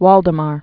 (wôldə-mär, väl-)